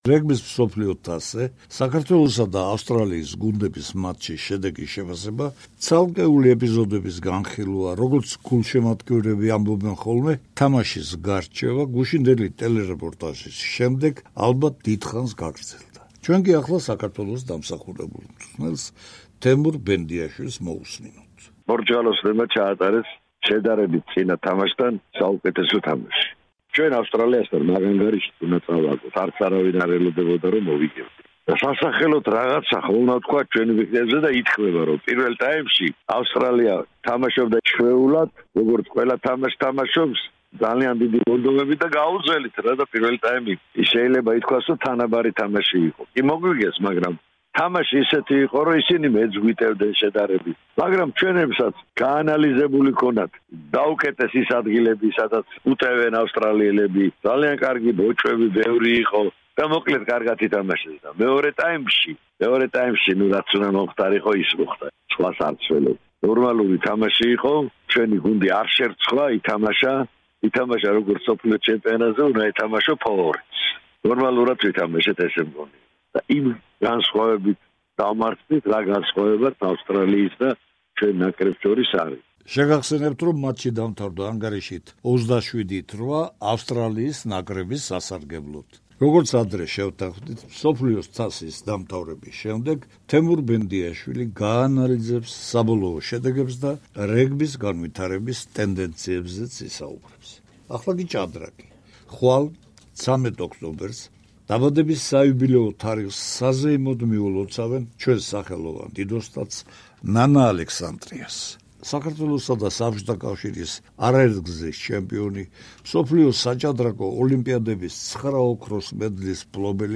ინტერვიუ სახელოვან მოჭადრაკე, დიდოსტატ ნანა ალექსანდრიასთან, რომლის დაბადების საიუბილეო თარიღი 13 ოქტომბერს აღინიშნება.